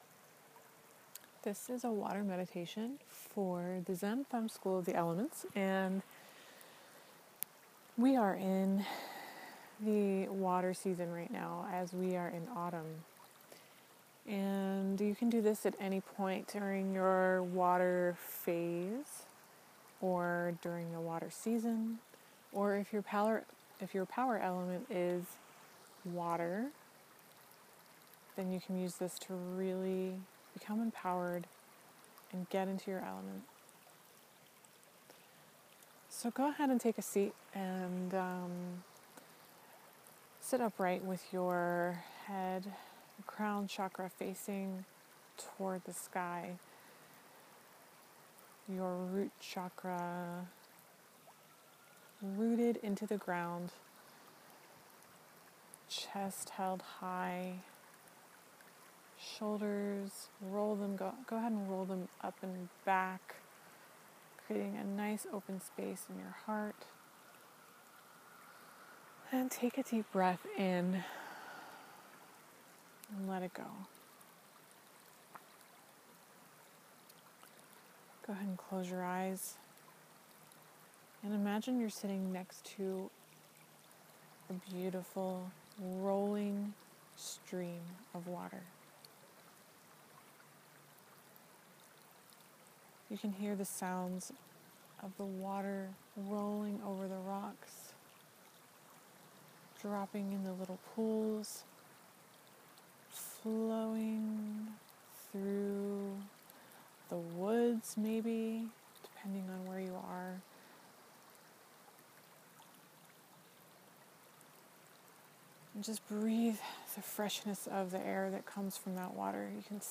Cleansing Ayurvedic Water Meditation for you.
Zen+Femme+Water+Meditation.m4a